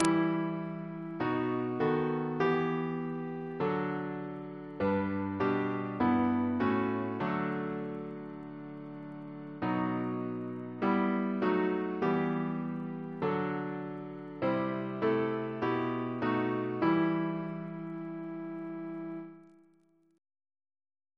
Double chant in E minor Composer: Ray Francis Brown (1897-1965) Reference psalters: ACP: 120